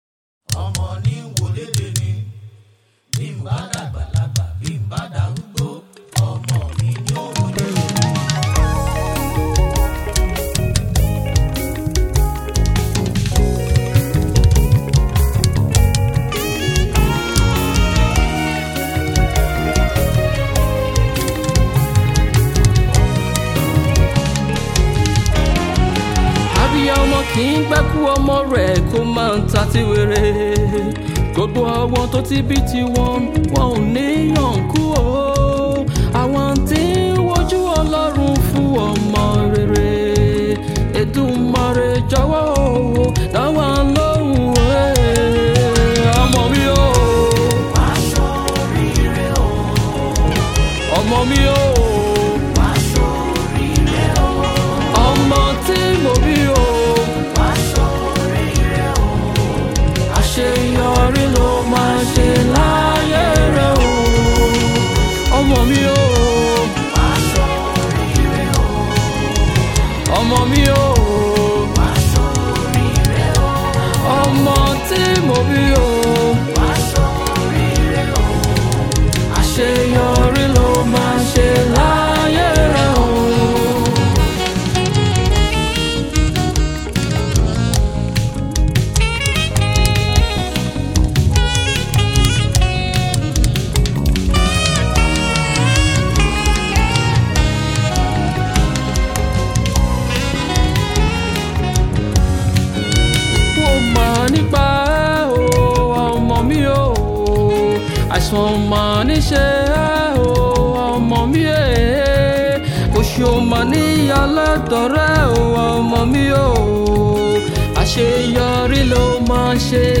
Yoruba prayer song